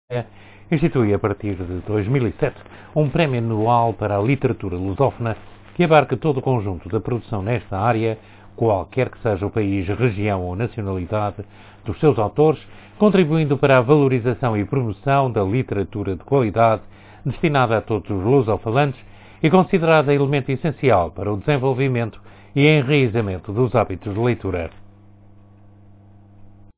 voice sample Amostra de voz multisampling
sample voice.mp3